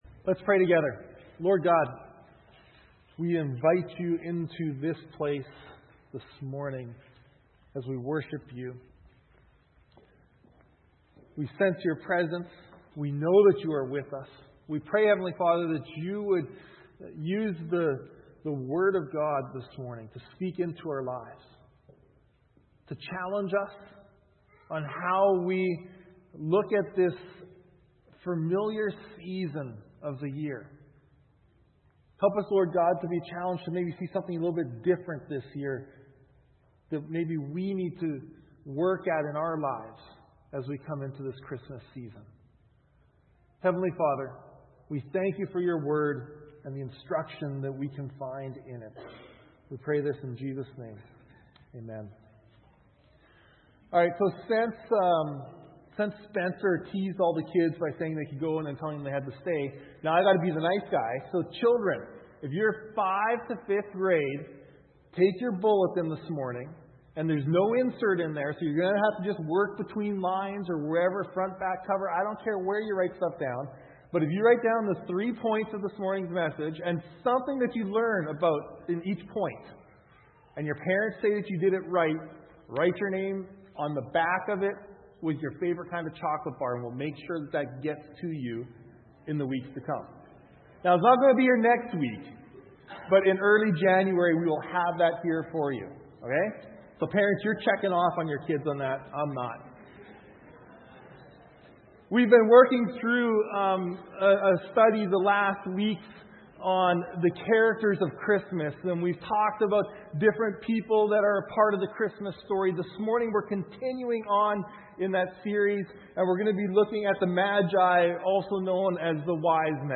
Sermons - Fairland Church